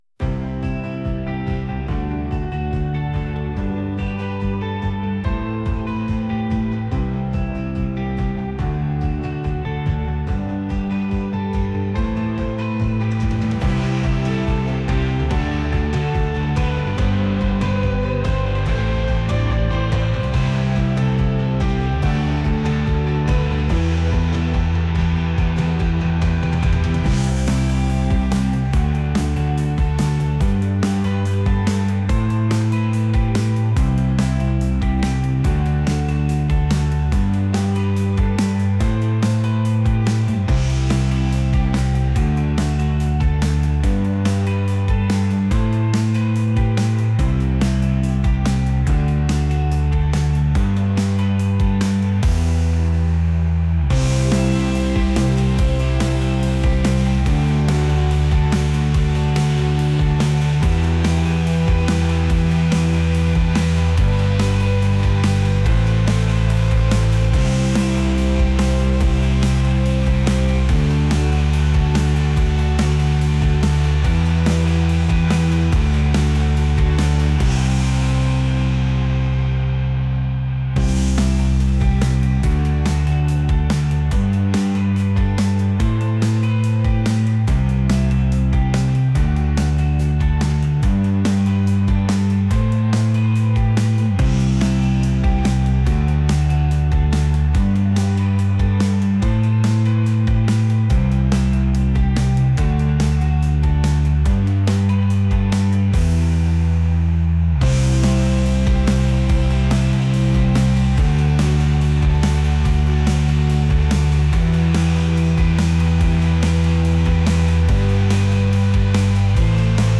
pop | indie